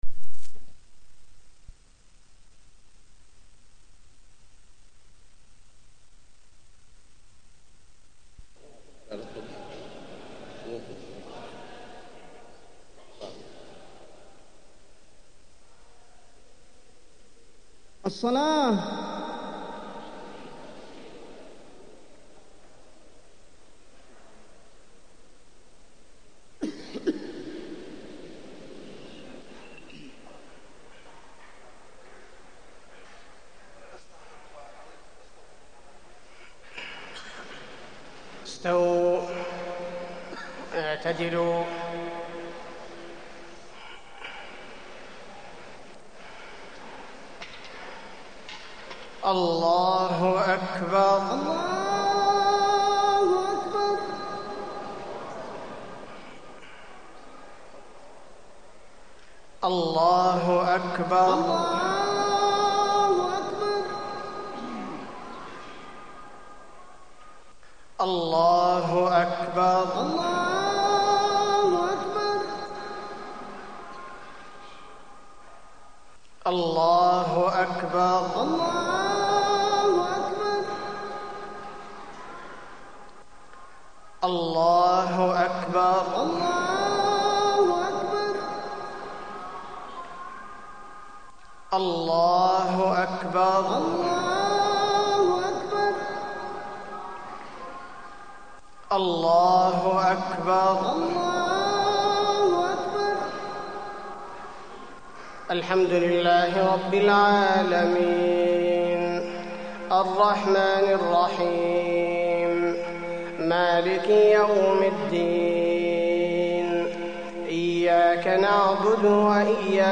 خطبة عيد الأضحى - المدينة - الشيخ عبدالباري الثبيتي
تاريخ النشر ١٠ ذو الحجة ١٤٢٣ هـ المكان: المسجد النبوي الشيخ: فضيلة الشيخ عبدالباري الثبيتي فضيلة الشيخ عبدالباري الثبيتي خطبة عيد الأضحى - المدينة - الشيخ عبدالباري الثبيتي